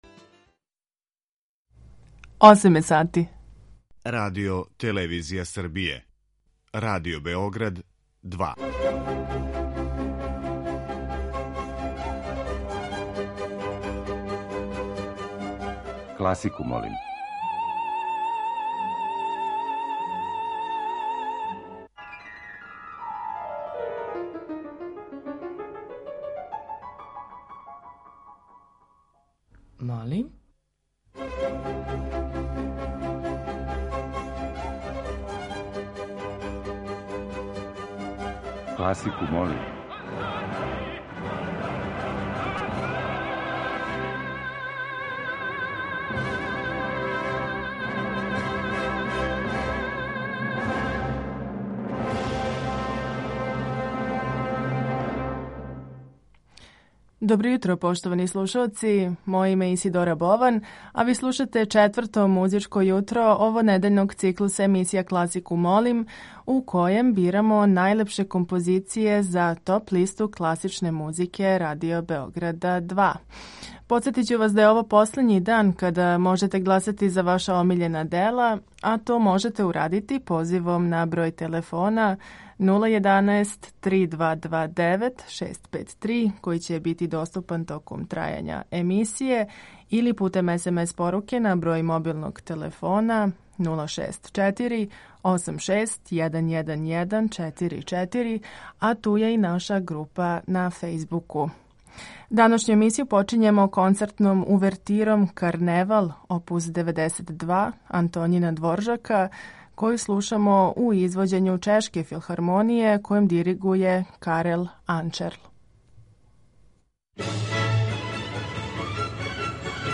Tema ciklusa nosi naslov „Marševi'.
klasika.mp3